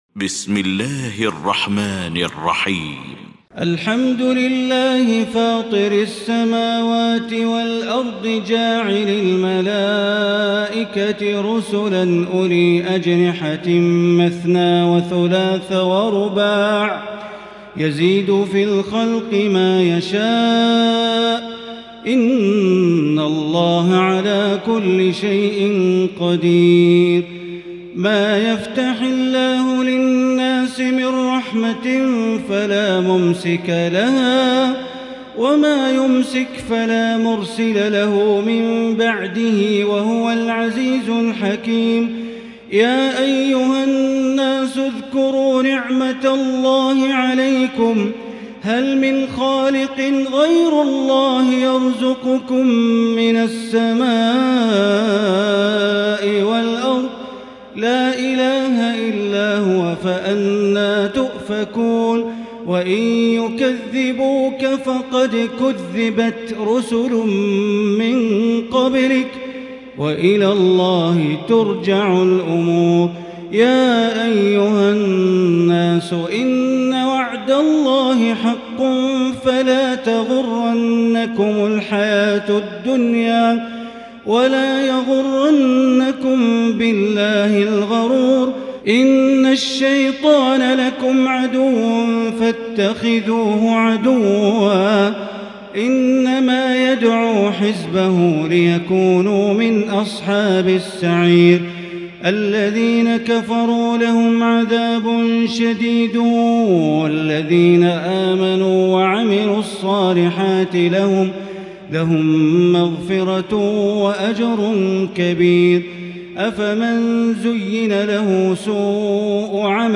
المكان: المسجد الحرام الشيخ: معالي الشيخ أ.د. بندر بليلة معالي الشيخ أ.د. بندر بليلة فاطر